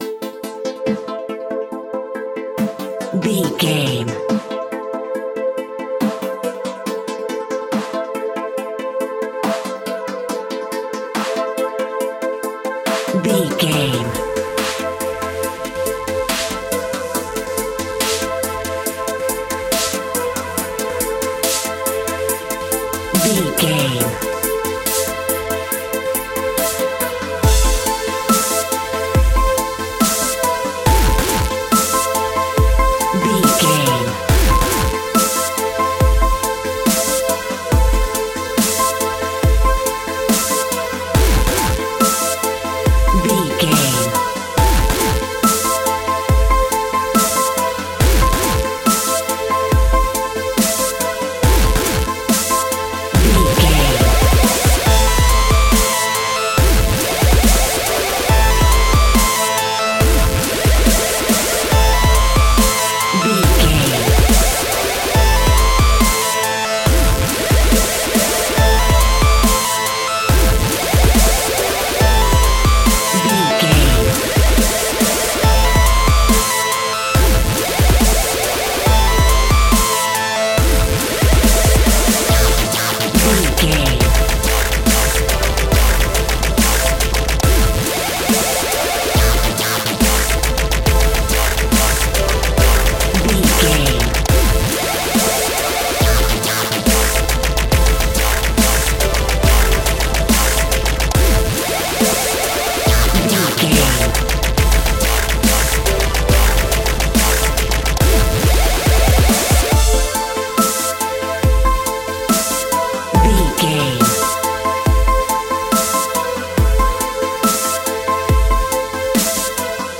Laid Back Dubstep Downtempo.
Aeolian/Minor
intense
futuristic
driving
repetitive
aggressive
dark
synthesiser
drum machine
piano
breakbeat
dubstep instrumentals
synth bass